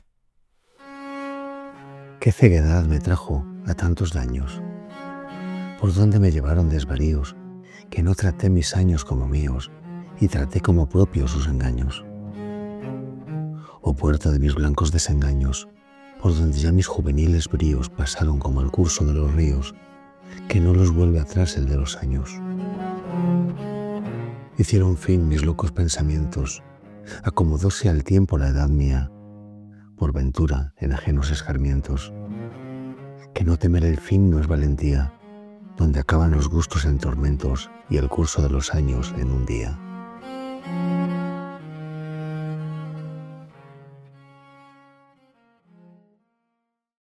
Que-ceguedad-me-trujo_music.mp3